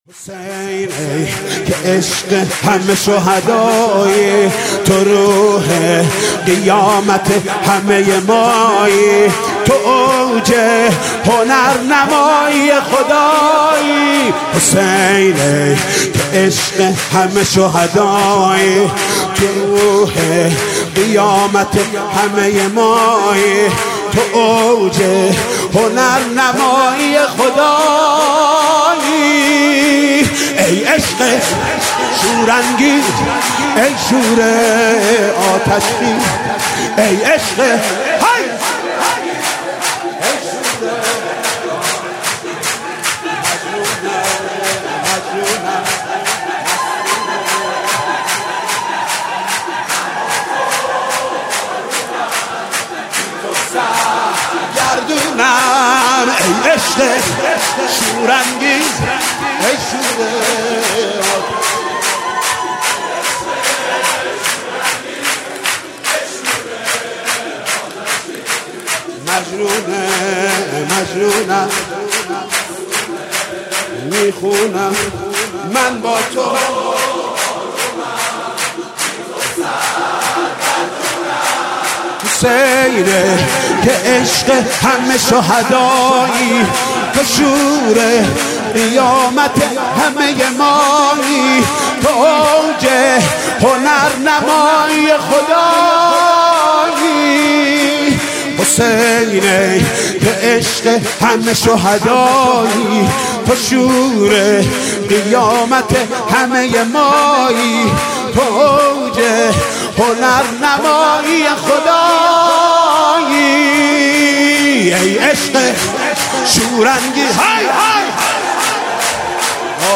سرود: حسین ای که عشق همه شهدایی